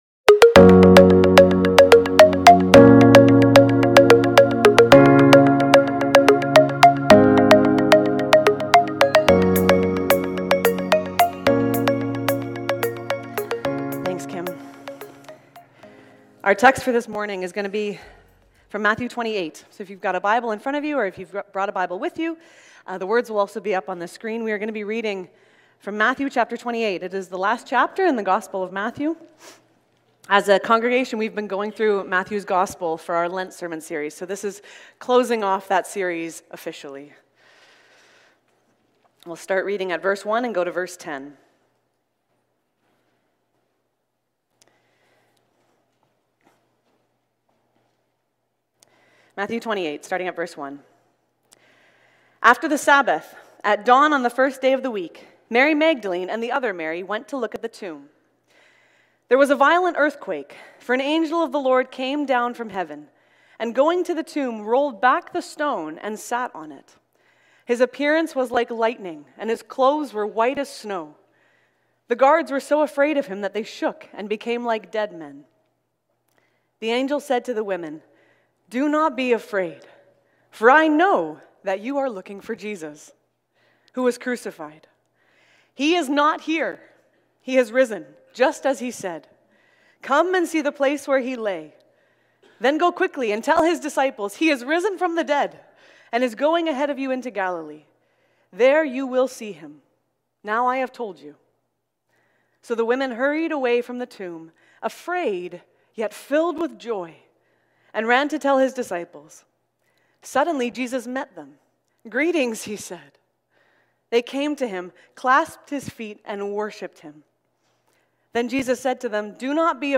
Willoughby Church Sermons | Willoughby Christian Reformed Church
This week we celebrate the glory of the resurrection with our Easter Service!